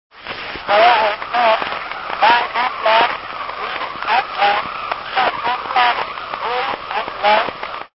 lam-clockx.mp3